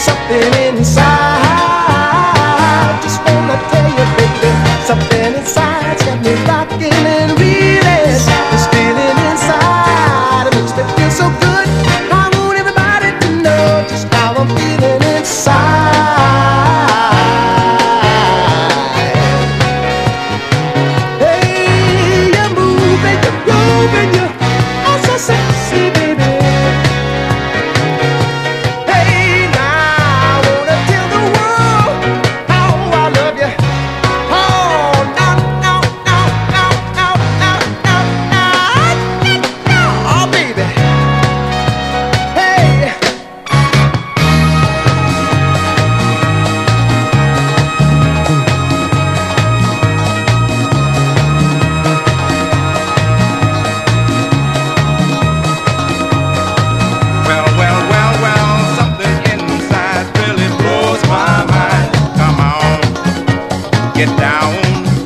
SOUL / SOUL / 70'S～
オルガン、ベースが歯切れよく弾むハッピー・ディスコな
ネットリとしたセクシー・ファンク